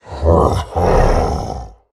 Minecraft Version Minecraft Version 25w18a Latest Release | Latest Snapshot 25w18a / assets / minecraft / sounds / mob / ravager / celebrate1.ogg Compare With Compare With Latest Release | Latest Snapshot
celebrate1.ogg